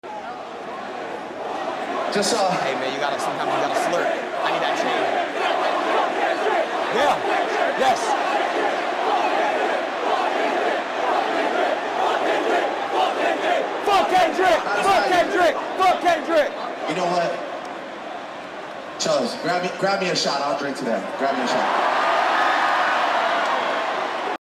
The UK vs US Crowd sound effects free download
The UK vs US Crowd chants debate over after this😭🔥